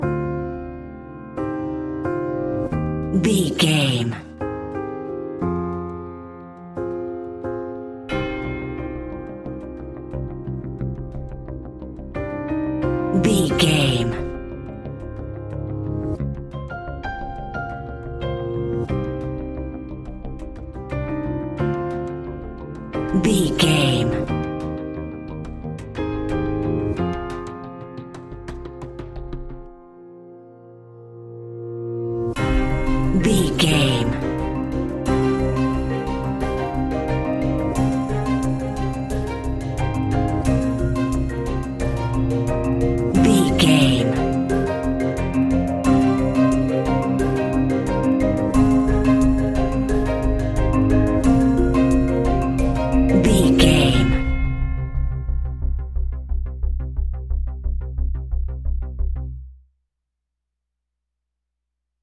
Ionian/Major
C♭
electronic
techno
trance
synths
synthwave
instrumentals